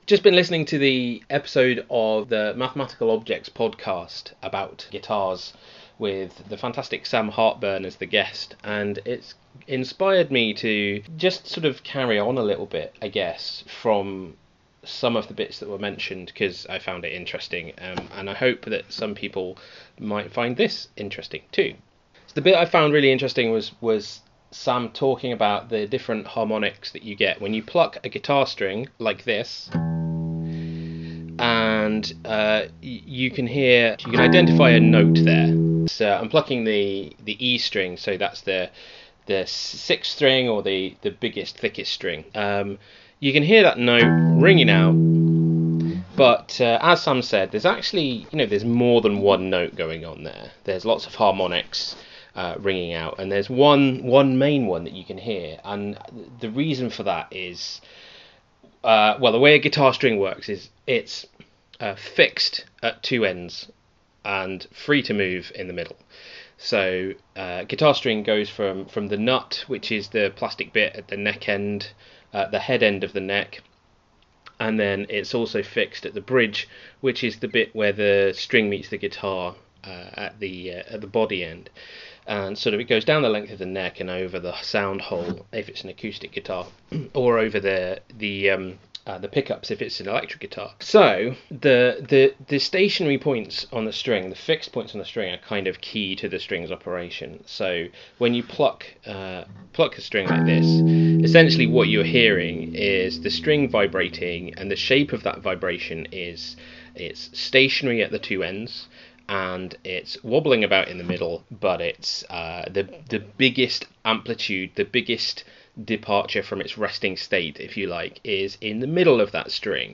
10 minutes of me jabbering, occasionally punctuated with the plucking of a guitar string.